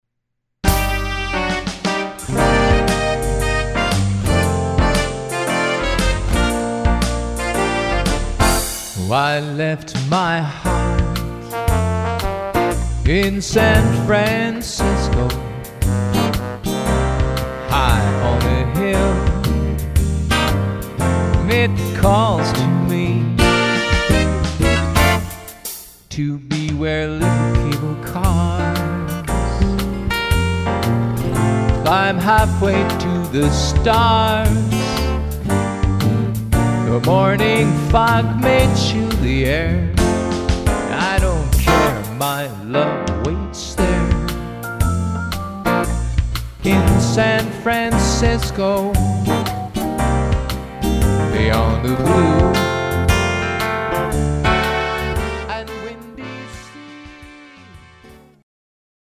a great Swing Arrangement